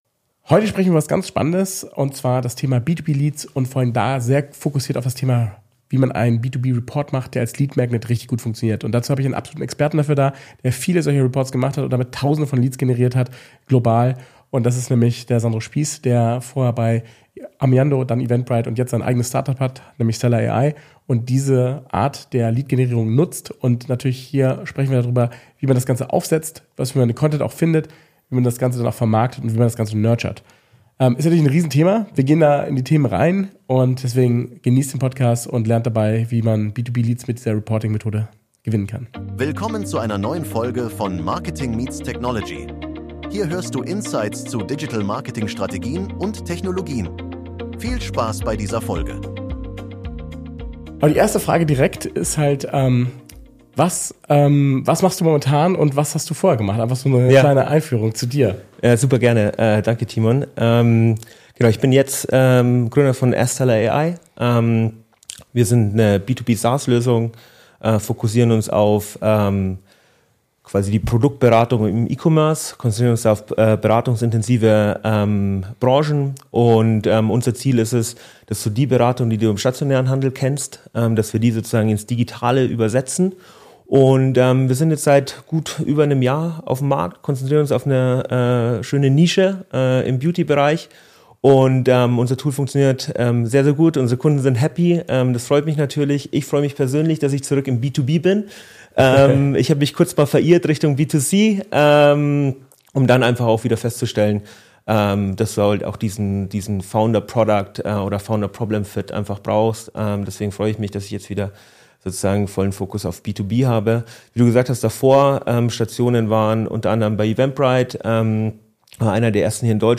B2B-Marketing Insights - Interview